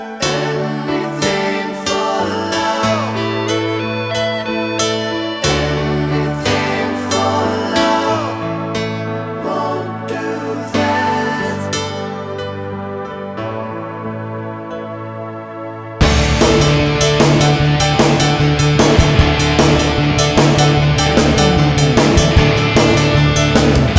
For Male Solo Rock 5:27 Buy £1.50